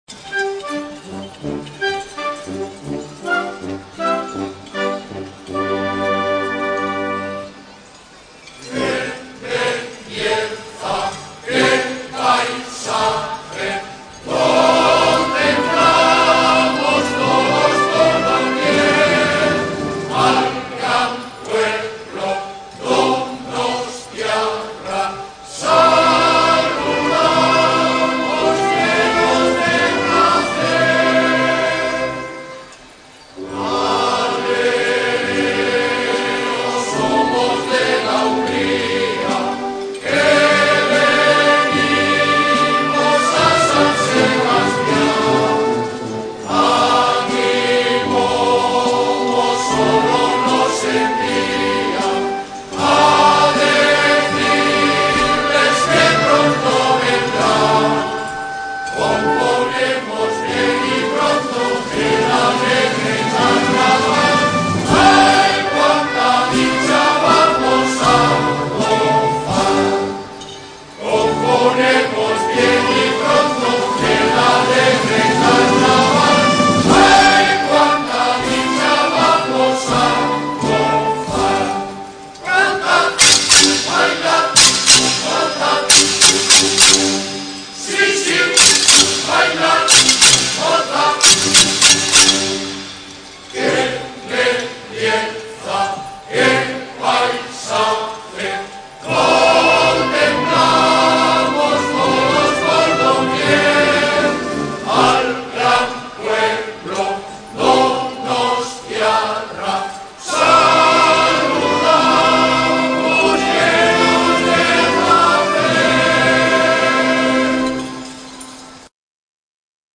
Coro.mp3